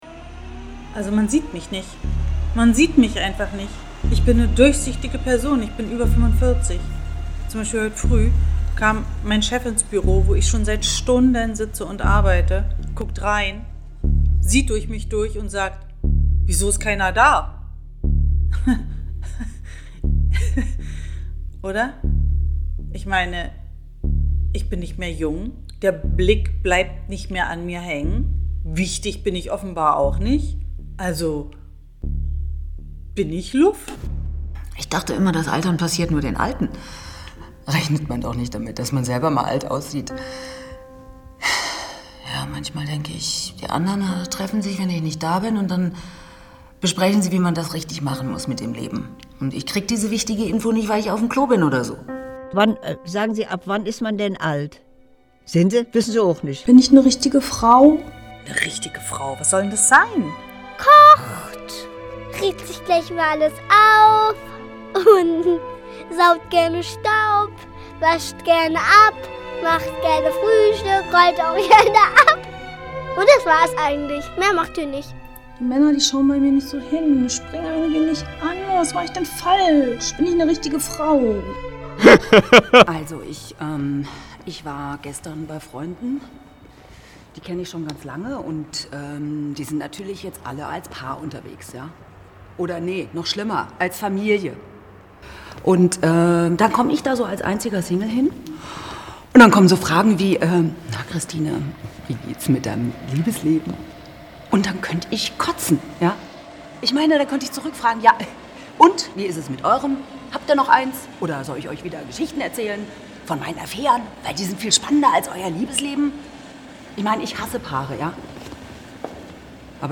die sistas/ Hörspiel Flüchtige Gedanken